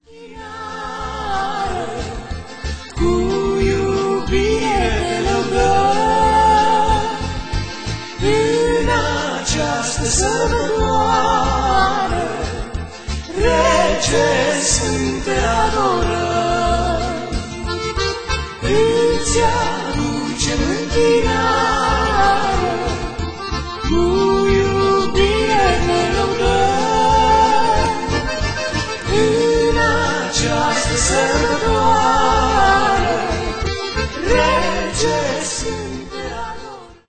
se datoreaza nu numai aranjamentului orchestral deosebit